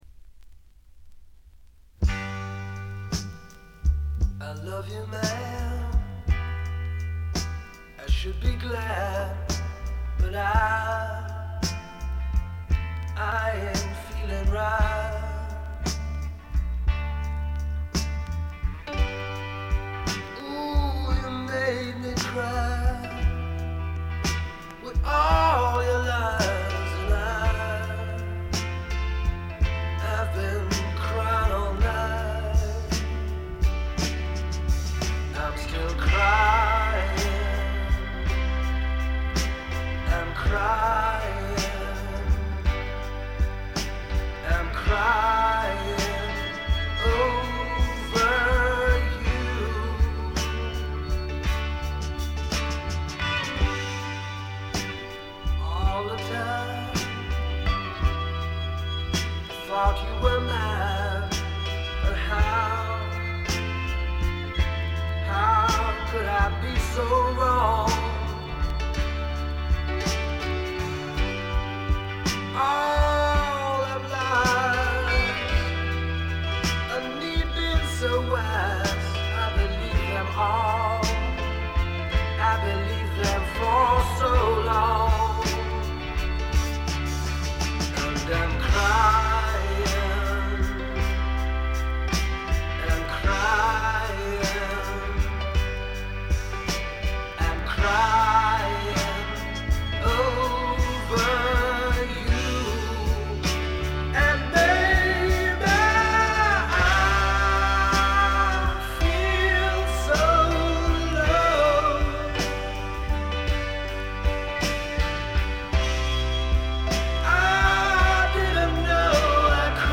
ごくわずかなノイズ感のみ。
ほのかない香るカントリー風味に、何よりも小粋でポップでごきげんなロックンロールが最高です！
試聴曲は現品からの取り込み音源です。
Rockfield Studios, South Wales